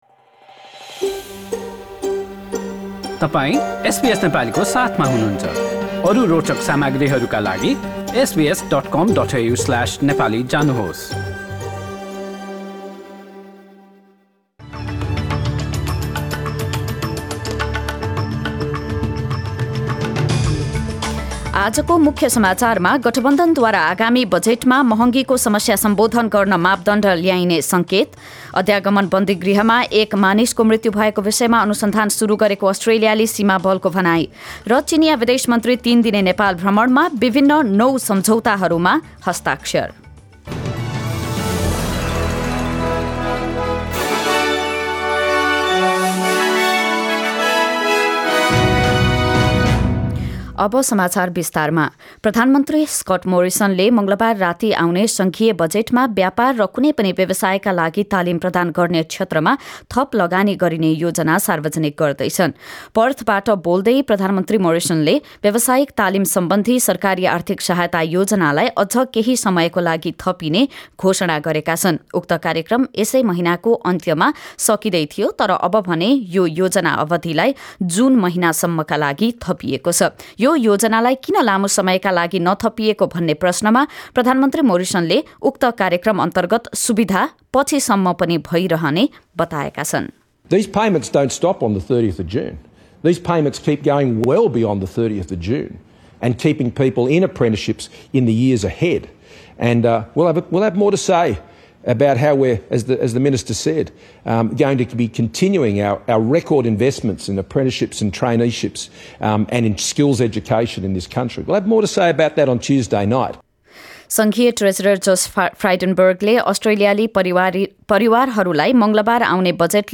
एसबीएस नेपाली अस्ट्रेलिया समाचार: आइतबार २७ मार्च २०२२